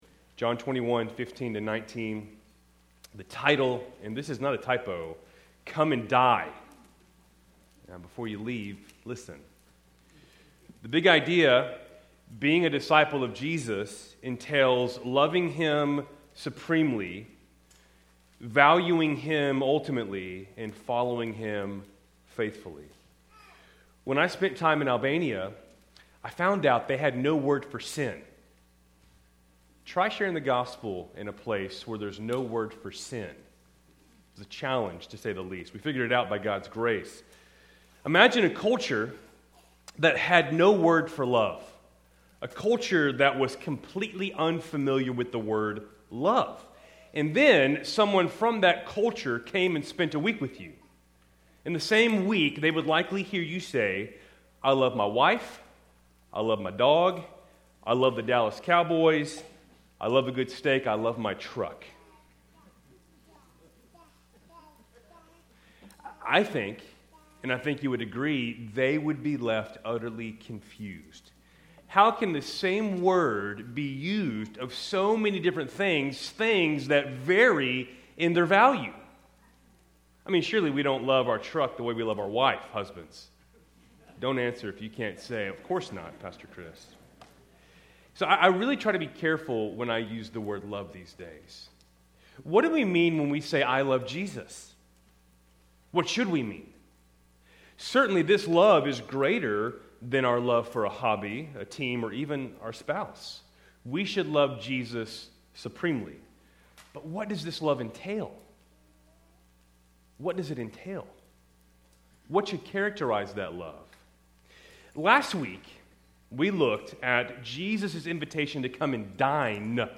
Keltys Worship Service, April 19, 2026